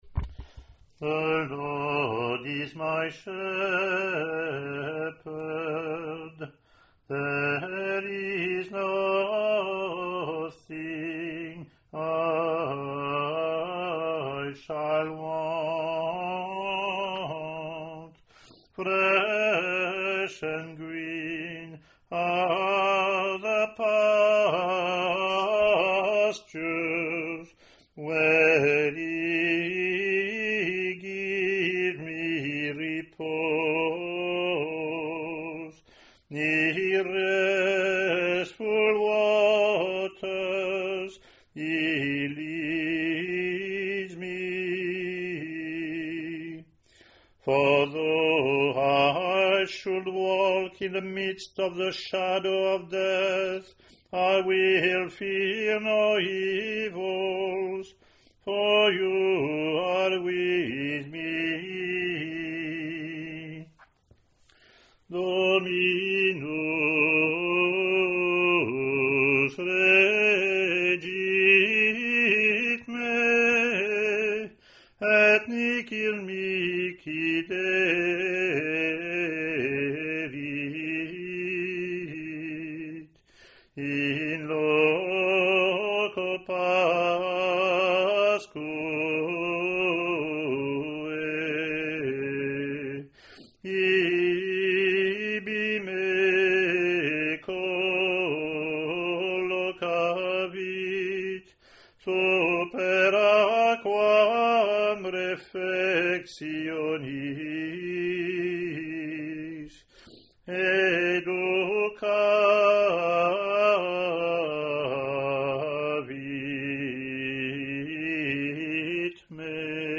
Year C English antiphon – English verse – Year A Latin antiphon + verse,
ot32c-comm-eng-pw-gm.mp3